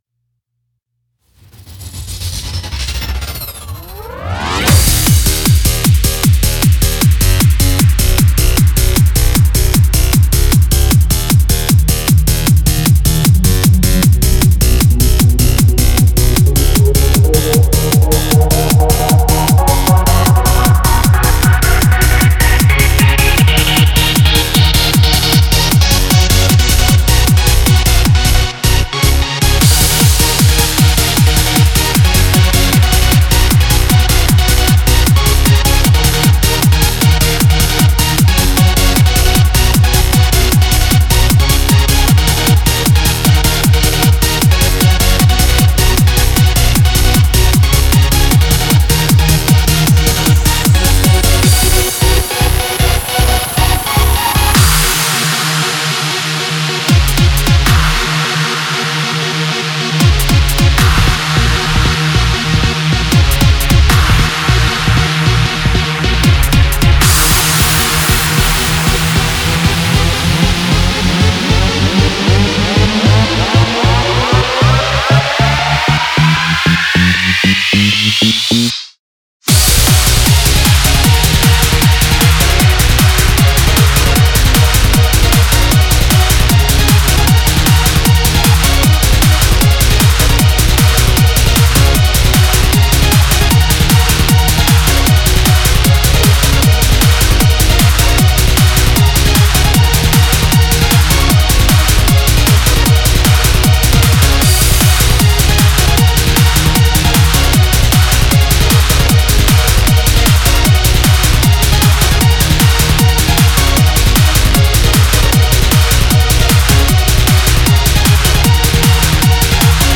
EDM系のシンセ音源を使用して制作したハイテンポでサイバーなサウンドとメロディックな印象が特徴のトランス楽曲です。
シンプル構成ながらスピード感のある長尺のダンス・ミュージックになっています。